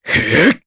Kasel-Vox_Casting2_jp.wav